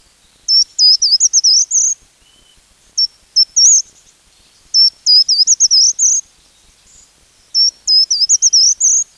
Short-toed Treecreeper
Certhia brachydactyla
Short-toed-Treecreeper.mp3